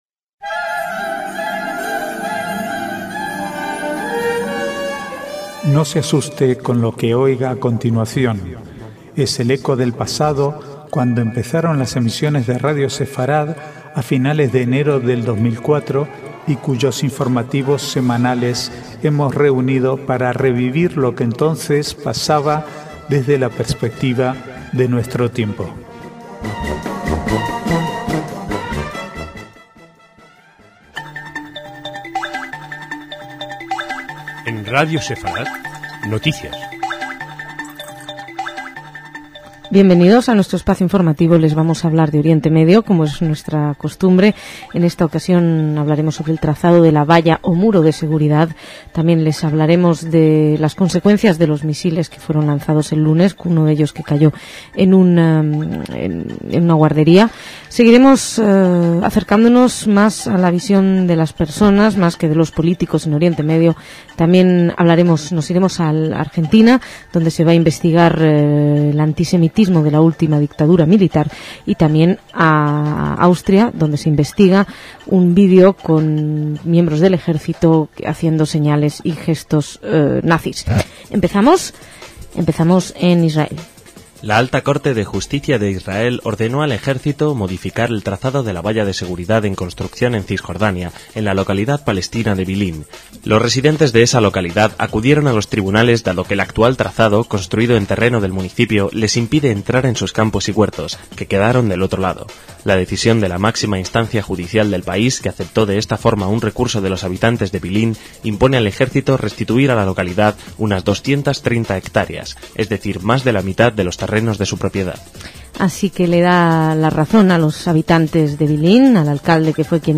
Archivo de noticias del 5 al 7/9/2007